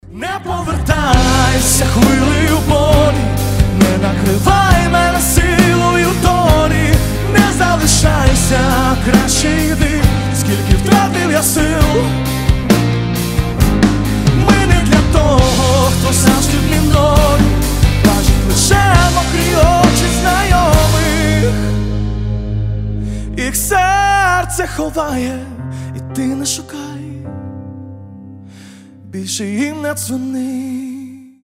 • Качество: 320, Stereo
красивые
душевные
акустика